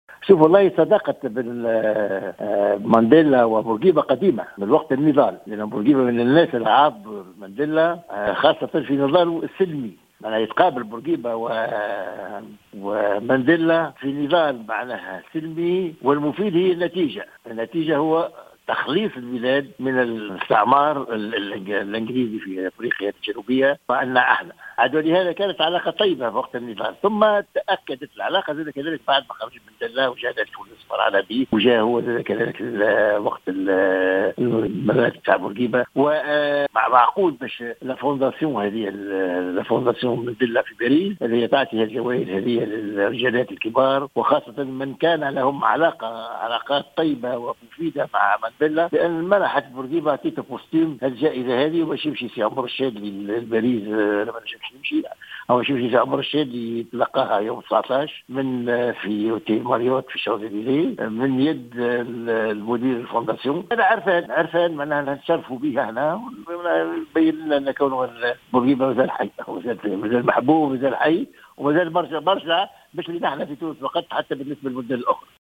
أكد وزير الداخلية الأسبق الطاهر بلخوجة في تصريح للجوهرة "اف ام" اليوم الخميس أن معهد مانديلا بباريس منح الزعيم بورقيبة جائزة مانديلا لسنة 2017عرفانا له بتاريخي النضالي الطويل.